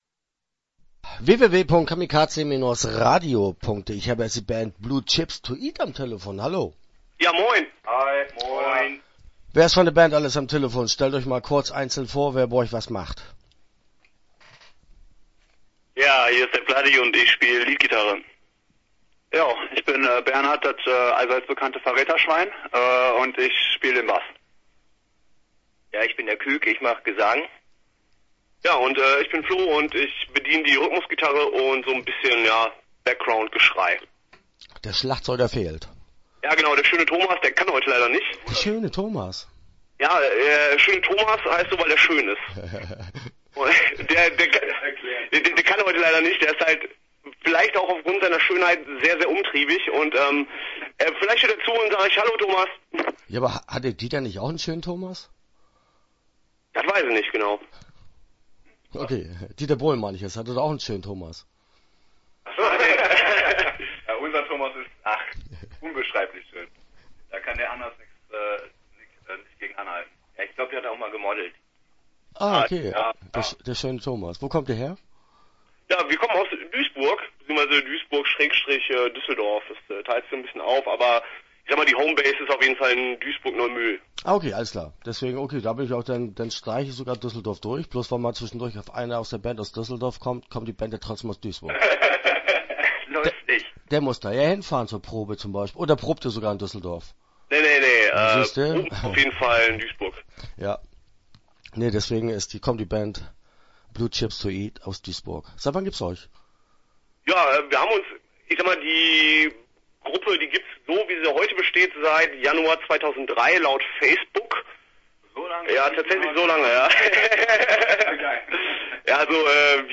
Blue Chips To Eat - Interview Teil 1 (12:37)